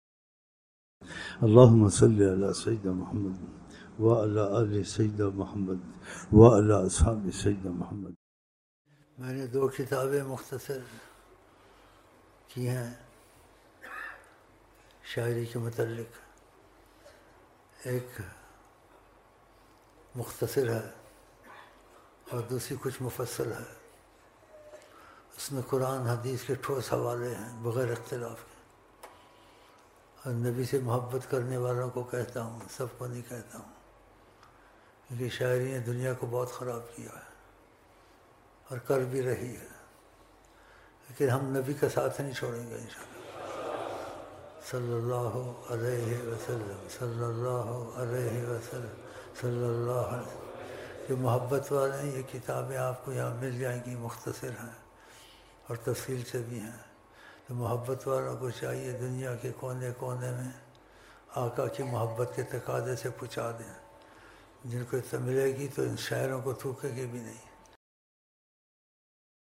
3 June 2011 Juma Mehfil